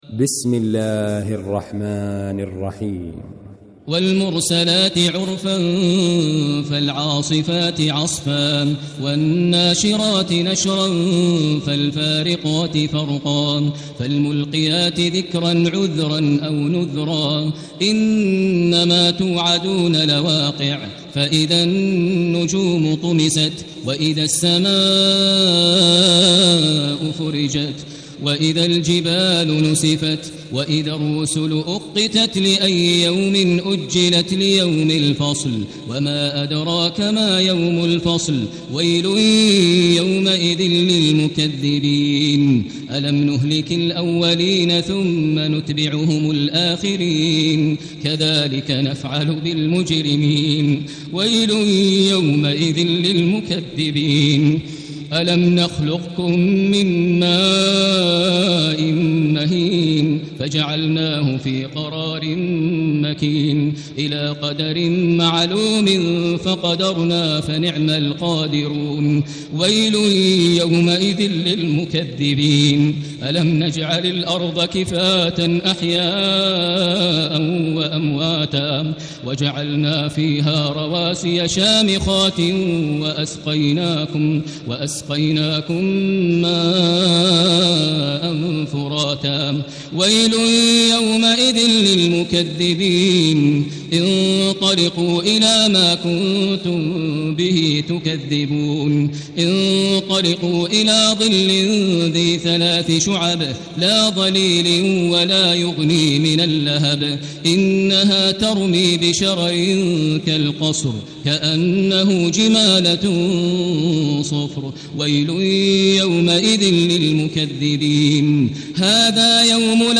المكان: المسجد الحرام الشيخ: فضيلة الشيخ ماهر المعيقلي فضيلة الشيخ ماهر المعيقلي المرسلات